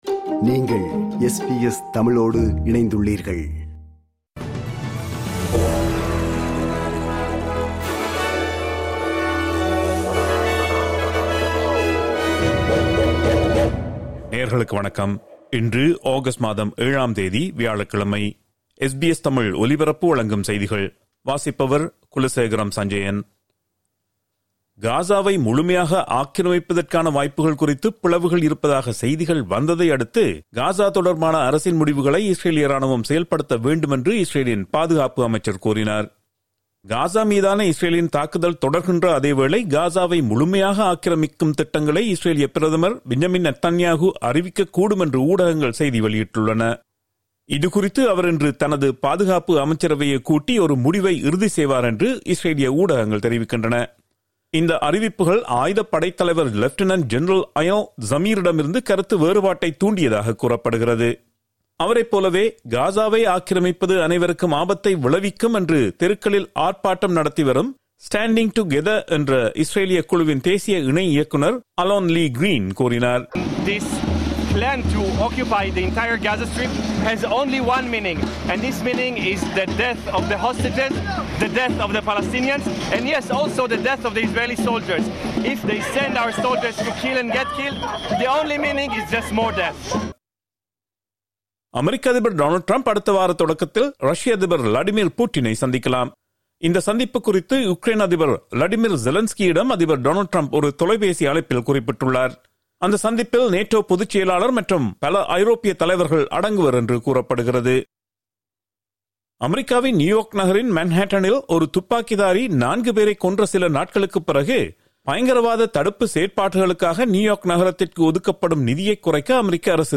SBS தமிழ் ஒலிபரப்பின் இன்றைய (வியாழக்கிழமை 07/08/2025) செய்திகள்.